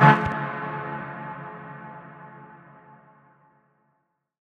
Atmos Dub Piano 2.wav